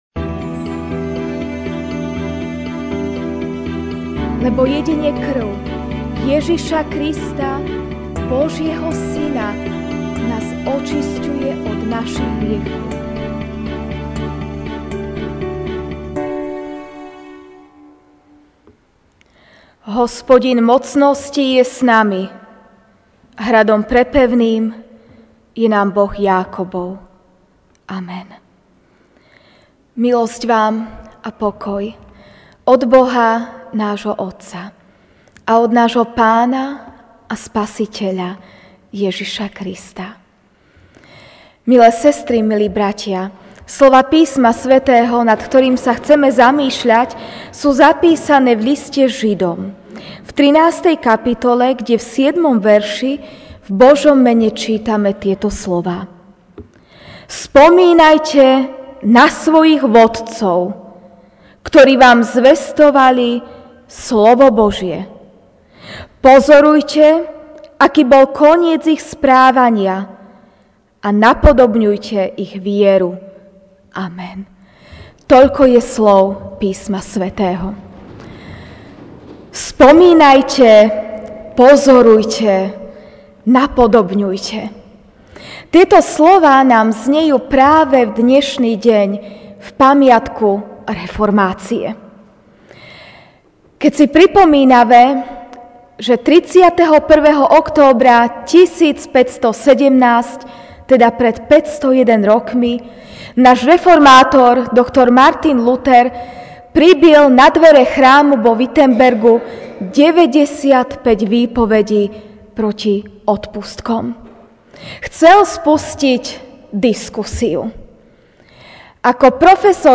Večerná kázeň: Pamiatka reformácie (Žd 13, 7)Spomínajte na svojich vodcov, ktorí vám zvestovali slovo Božie.